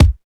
59 KICK.wav